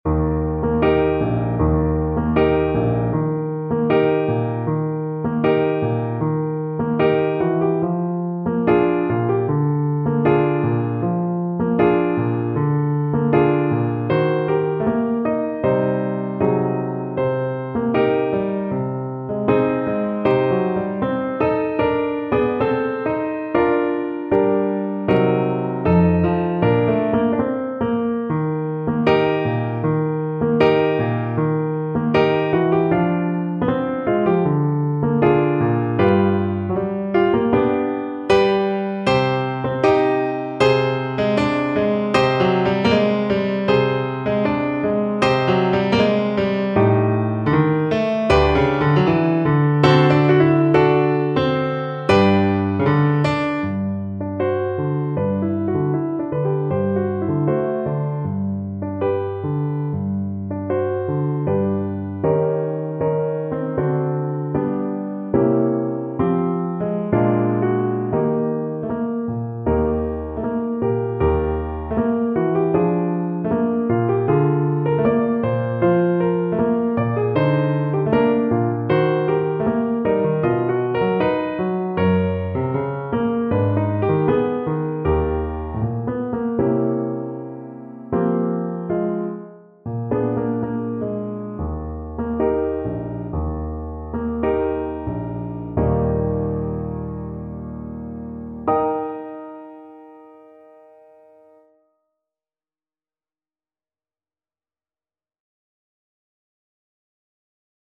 French Horn
Eb major (Sounding Pitch) Bb major (French Horn in F) (View more Eb major Music for French Horn )
Andantino (View more music marked Andantino)
2/4 (View more 2/4 Music)
Eb4-Ab5
Classical (View more Classical French Horn Music)
albeniz_tango_HN_kar3.mp3